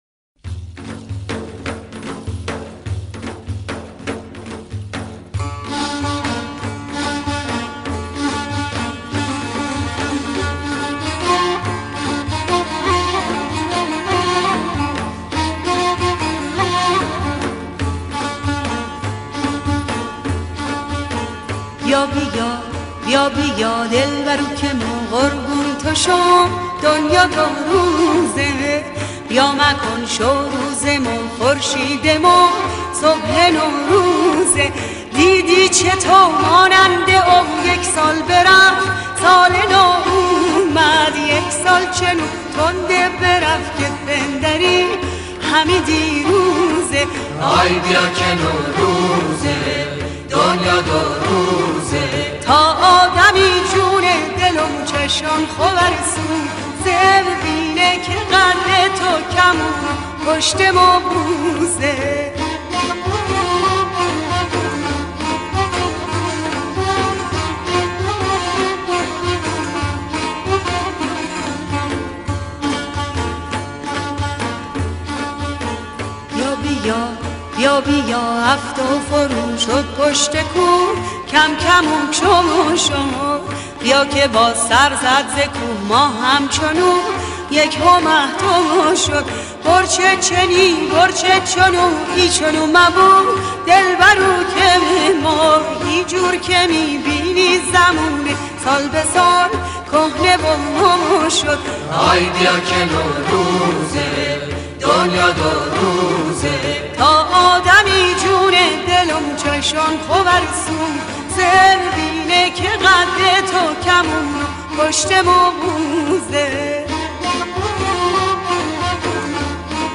موسیقی خراسان جنوبی